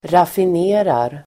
raffinera verb, refine Grammatikkommentar: A & x Uttal: [rafin'e:rar] Böjningar: raffinerade, raffinerat, raffinera, raffinerar Definition: rena (råmaterial) Exempel: raffinerad råolja (refined crude oil)
raffinerar.mp3